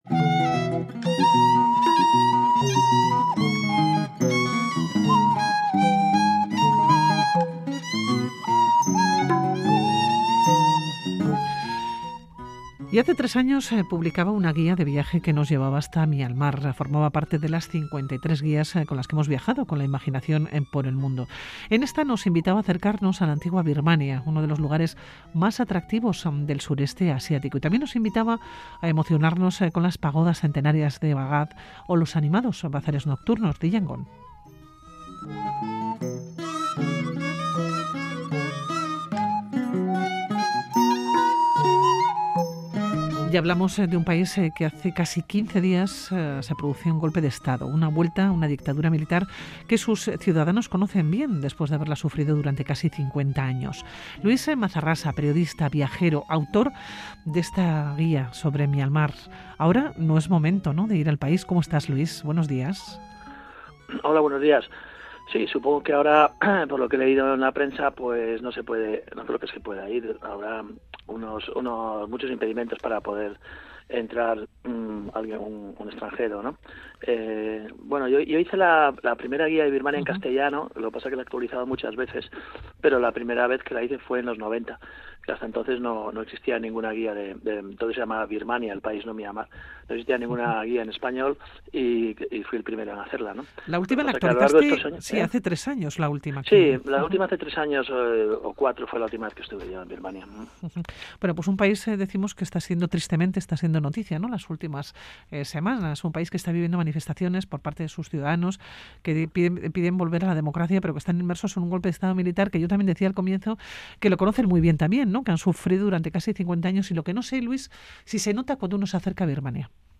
Radio Vitoria AVENTUREROS Birmania, un golpe militar en el paraíso asiático.